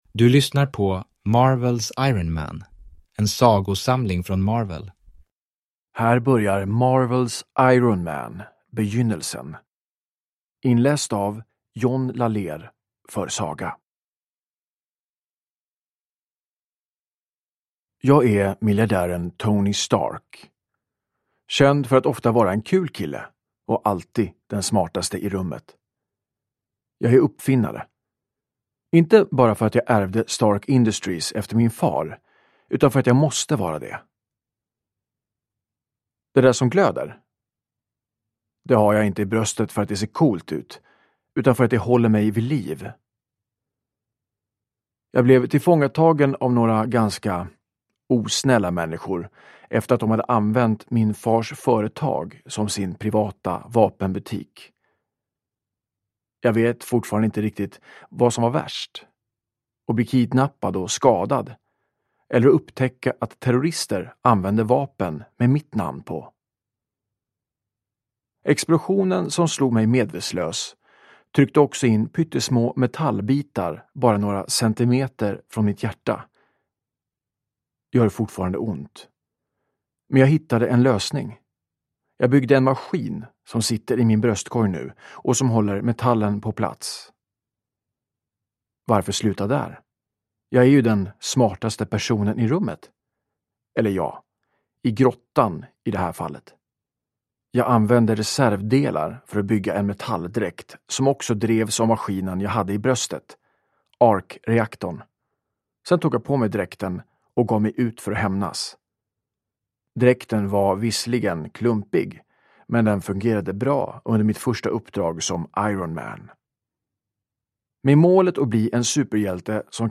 Marvels Iron Man (ljudbok) av Marvel